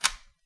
sfx_reload_4.mp3